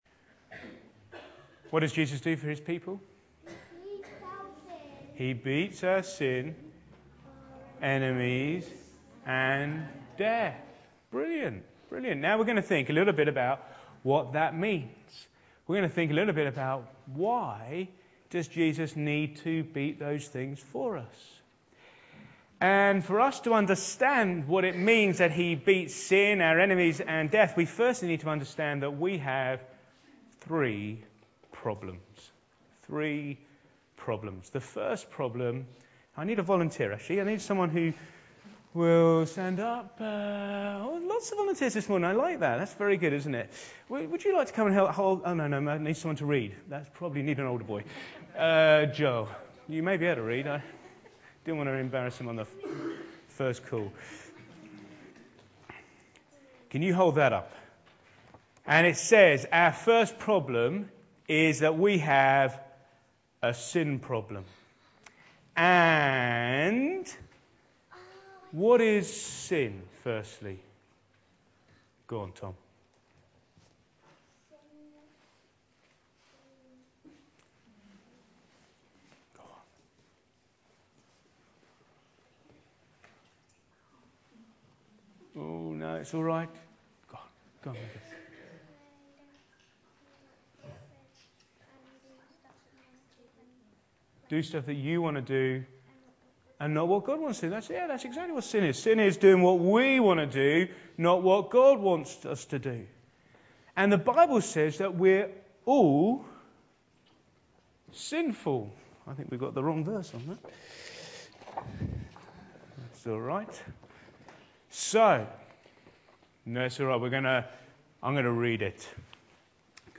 In our All Age service we talked about what that means.